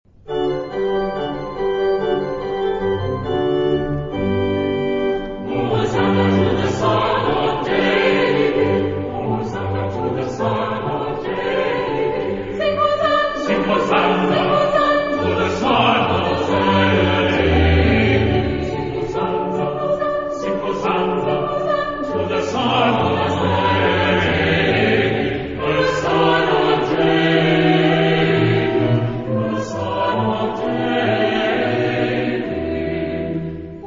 Genre-Style-Form: Sacred ; Section of the mass
Mood of the piece: allegro moderato
Type of Choir: SAH  (3 mixed voices )
Instrumentation: Piano  (1 instrumental part(s))
Tonality: G major
sung by The Oxford Choir conducted by Bob Chilcott